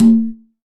9LOCONGOP.wav